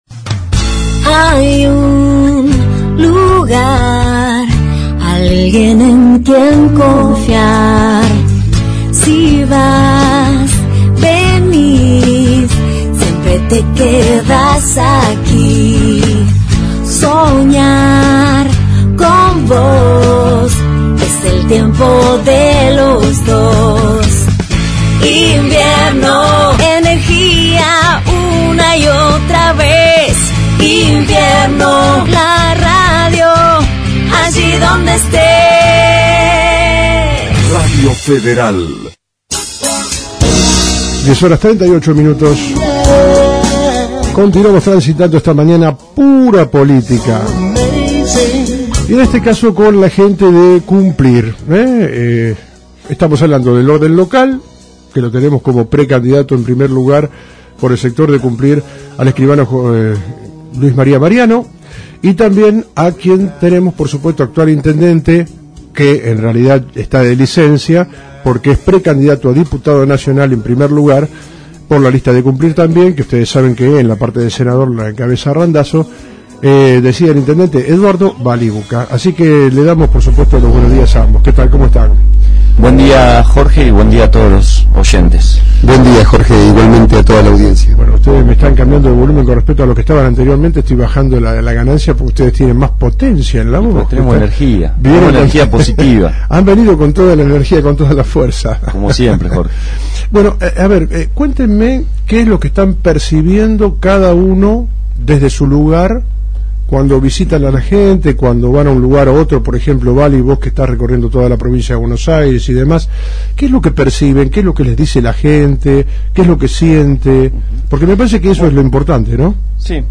HABLAN LOS CANDIDATOS